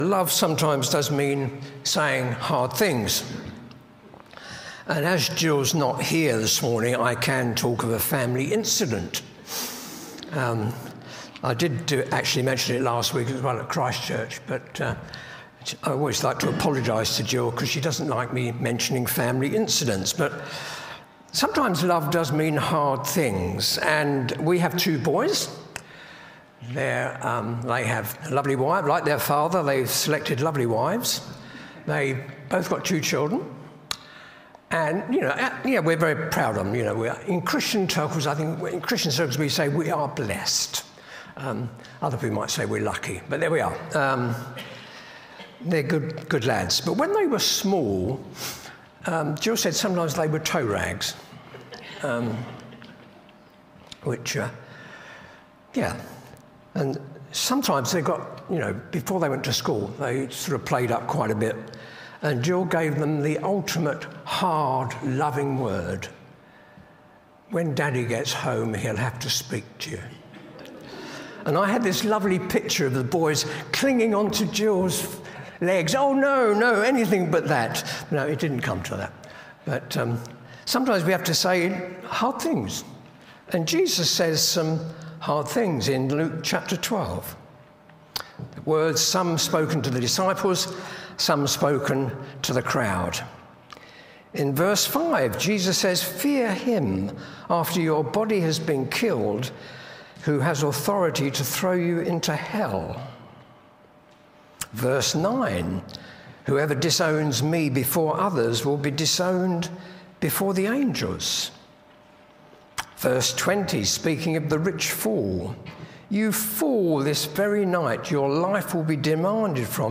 Reconciliation Preacher